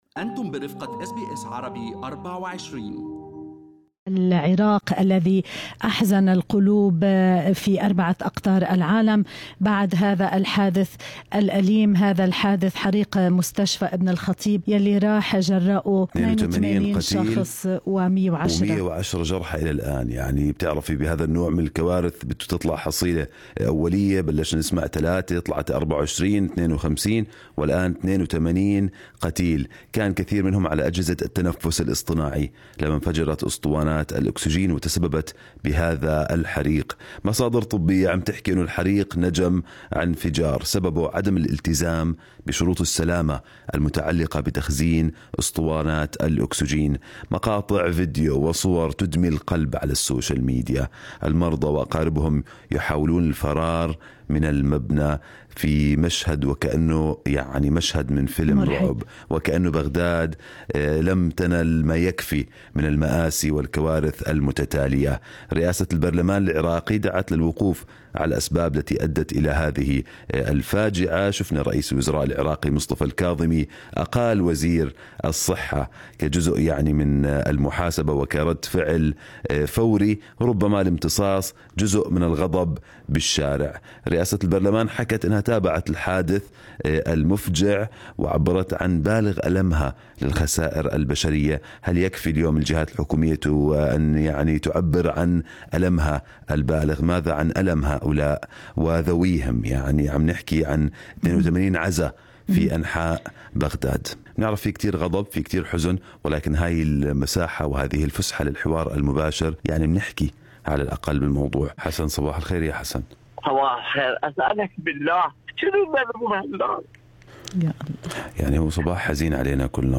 Aftermath of a fire at Ibn Al-Khatib Hospital, south of Baghdad, Iraq, Source: EPA وتحدث برنامج صباح الخير أستراليا مع عدد من أبناء الجالية العربية عبر أثير راديو أس بي أس عربي24 عن تداعيات الحادثة المأساوية في بغداد.
أول المتصلين باكيا